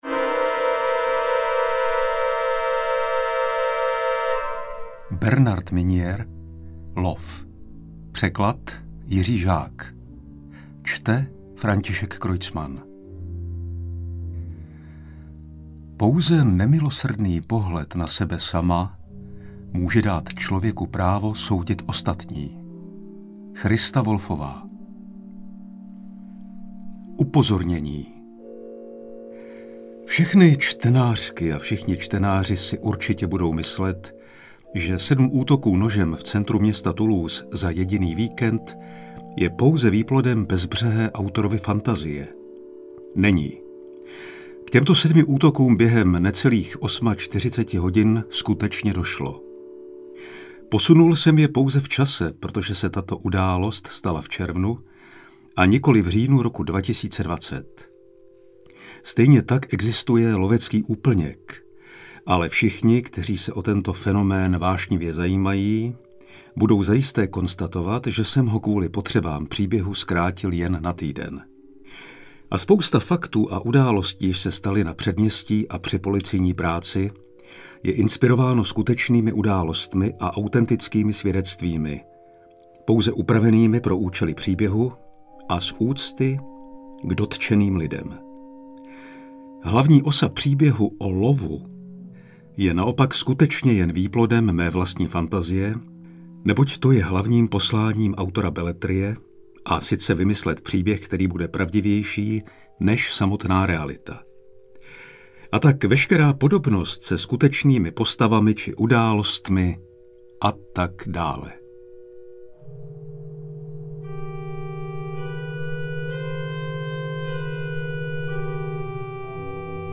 Interpret:  František Kreuzmann
AudioKniha ke stažení, 66 x mp3, délka 12 hod. 43 min., velikost 695,2 MB, česky